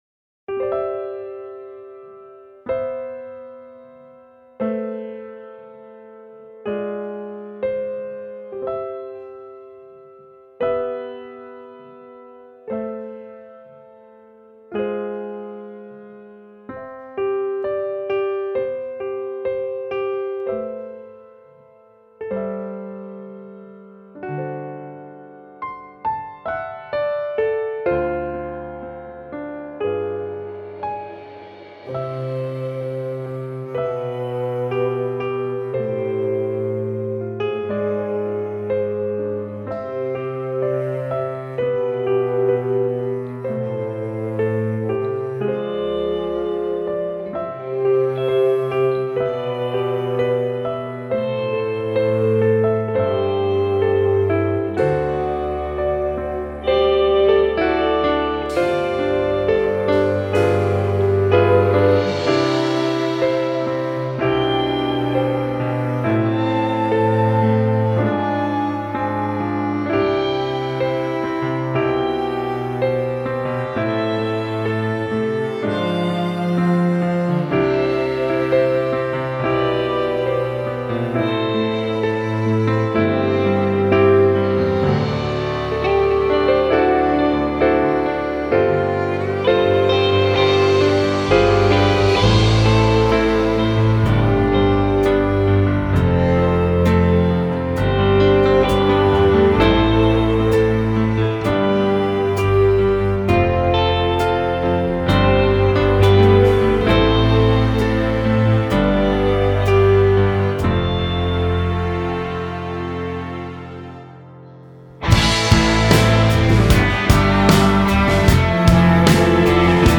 NoLeadVocal   Psalm 46 Rock God, the Refuge of His People. Piano, guitar, strings